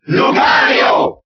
File:Lucario Cheer German SSBB.ogg
Lucario_Cheer_German_SSBB.ogg.mp3